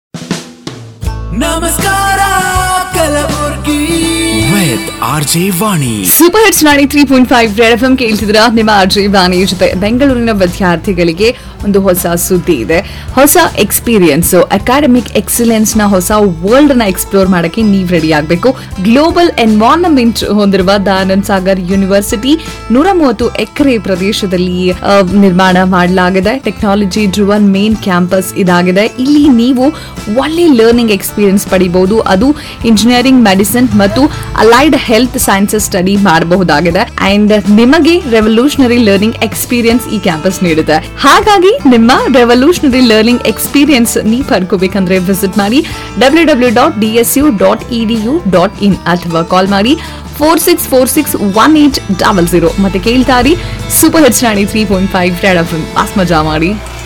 RJ Mentions on Radio Mirchi, Mumbai
The RJ will give a natural, unscripted mention of your brand during their show for about 25-45 seconds, helping promote your brand authentically.